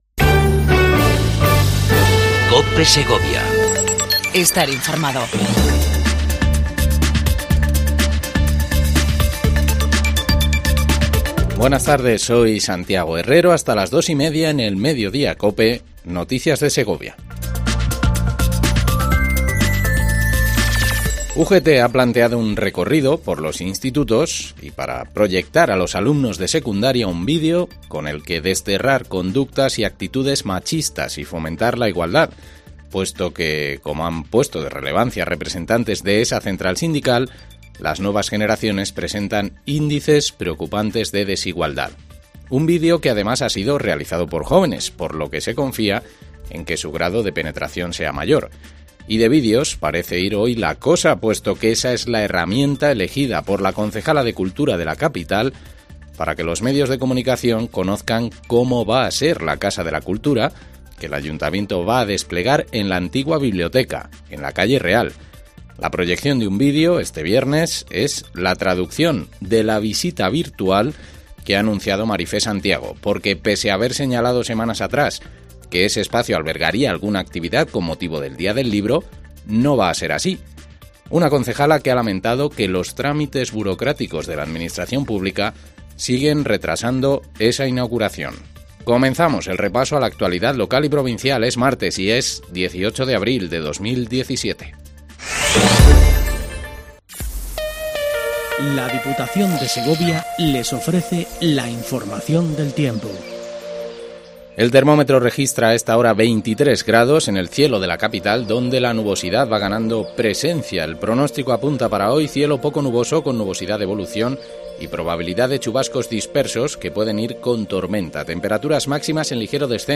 INFORMATIVO MEDIODIA COPE EN SEGOVIA 18 04 17